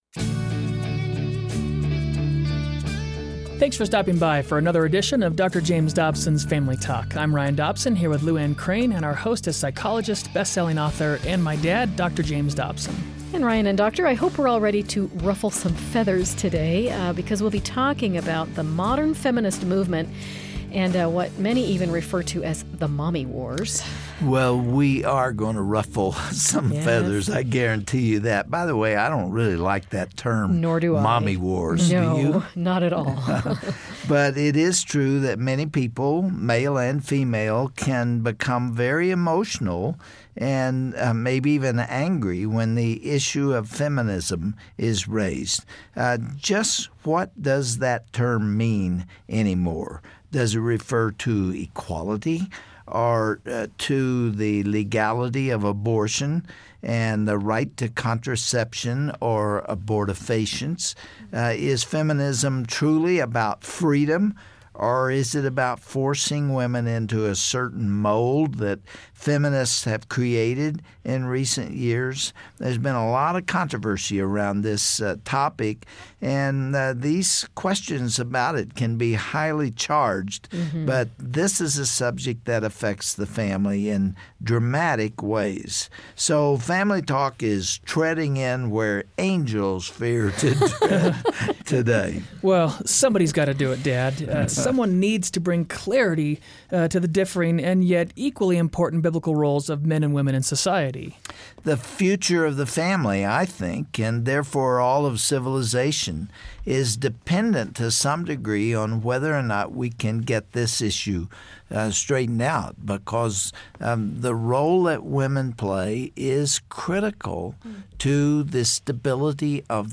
Dr. James Dobson will walk where angels fear to tread, by discussing the status of the modern feminist movement. Is it about equality and freedom, or is it about abortion, and forcing women into a specific vision of womanhood? Find out on this provocative discussion of the role of women in the family, and in culture.